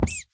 rabbit_hurt3.ogg